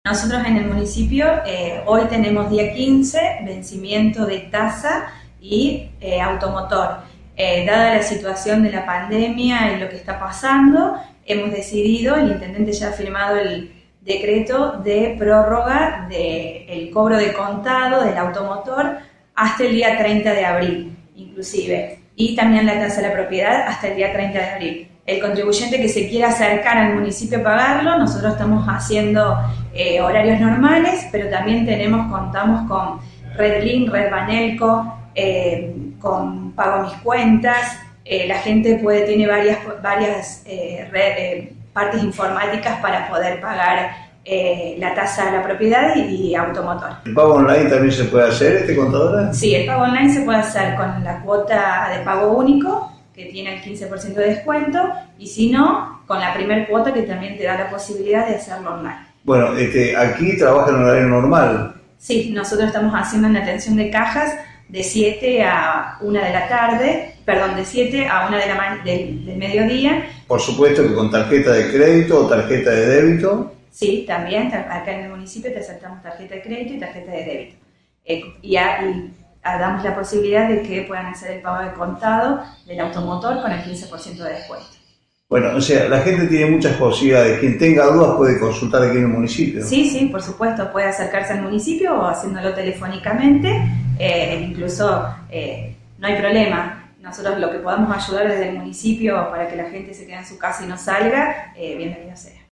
La Municipalidad de Leones prorrogó el vencimiento del impuesto a los automotores y la tasa por servicios a la propiedad hasta el día 30 de abril. Así lo informó la secretaria de finanzas, Lucrecia Peretti.